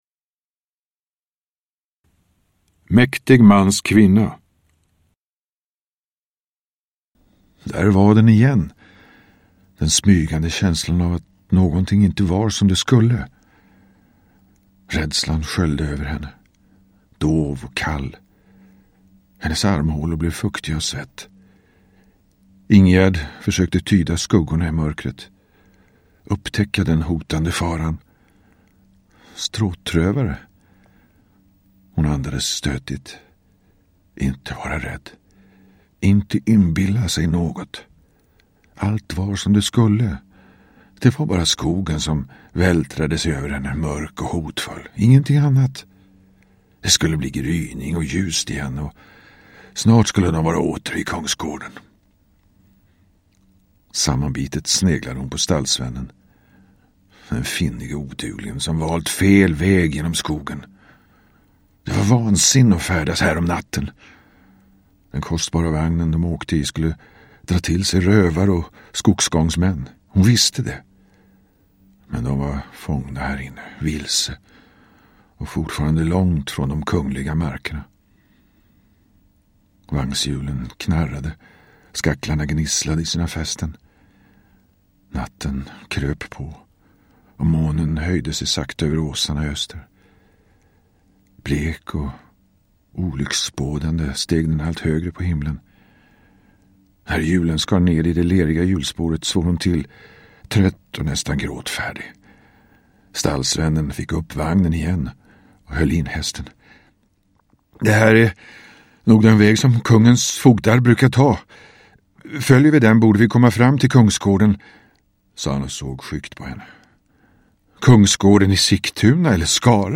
Downloadable Audiobook
Lyssna på Torsten Wahlund, vår "meste" uppläsare!